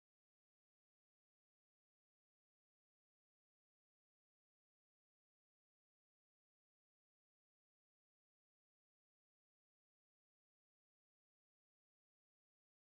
Kinderspiele: Wir treten auf die Kette
Tonart: G-Dur
Taktart: 4/8
Tonumfang: große Sexte